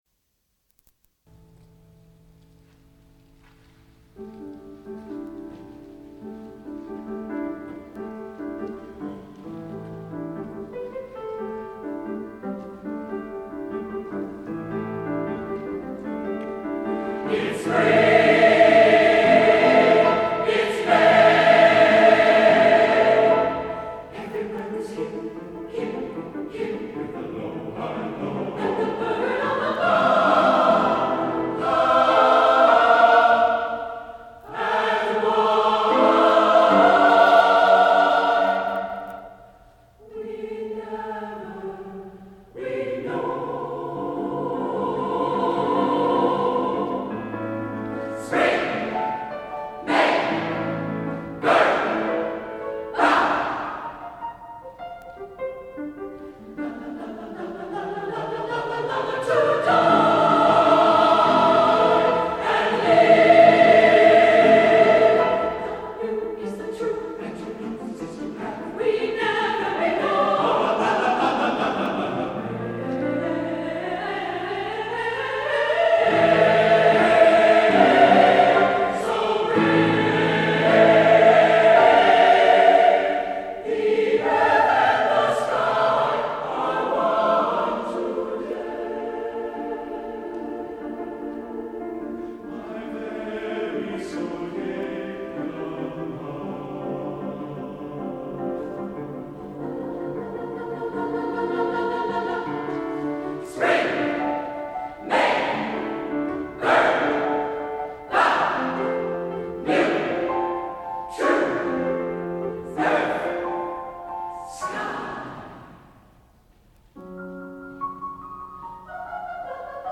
for SATB Chorus and Piano (1993)